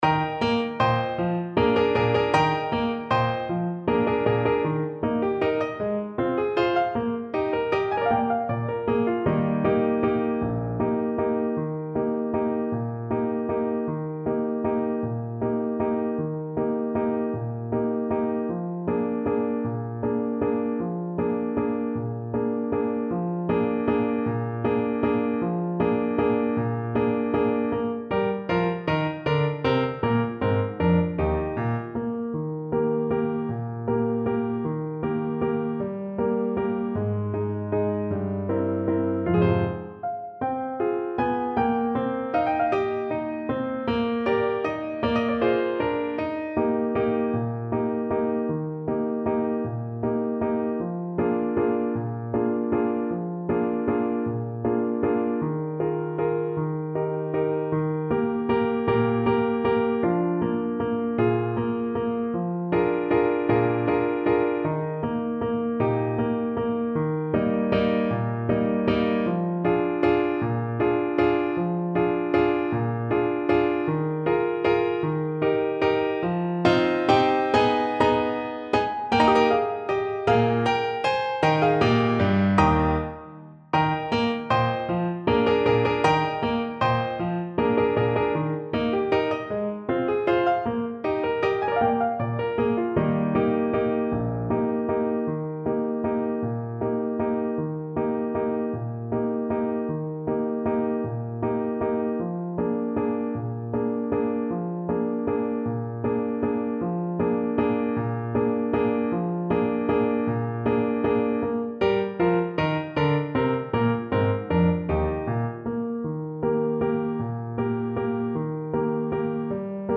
Allegretto . = c.52
6/8 (View more 6/8 Music)